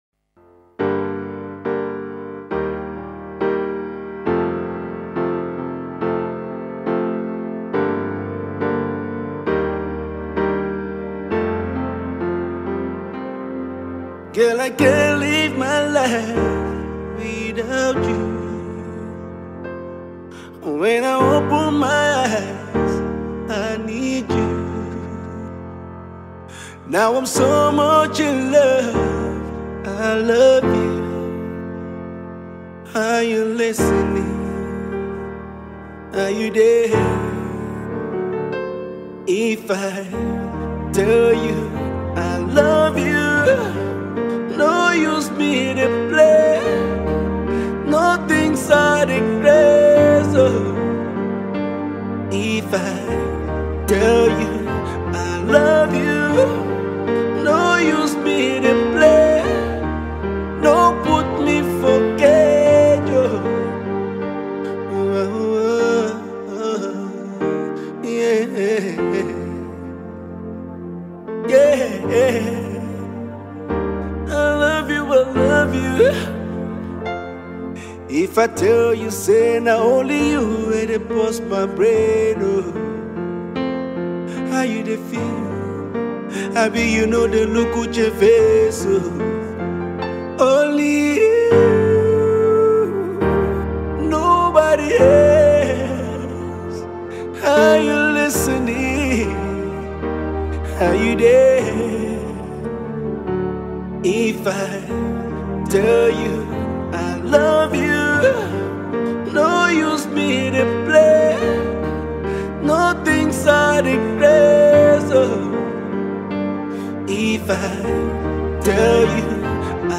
new love tempo song